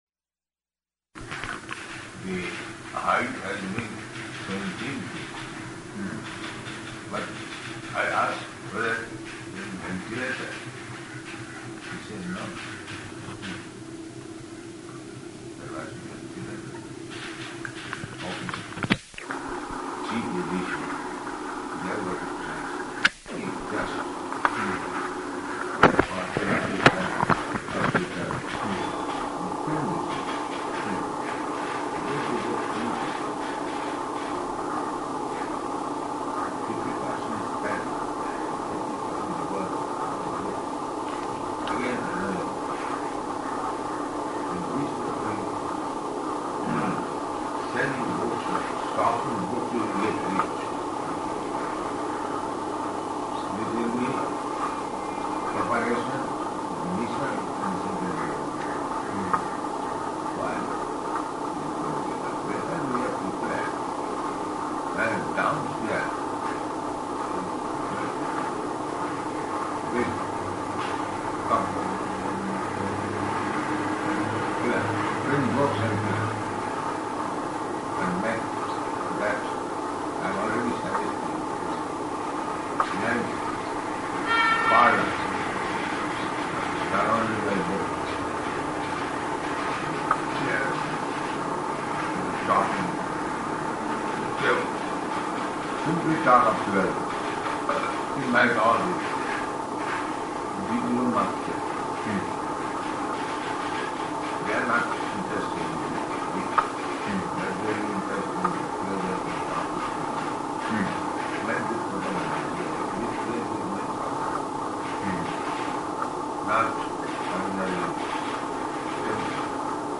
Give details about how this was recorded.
-- Type: Conversation Dated: November 12th 1971 Location: Delhi Audio file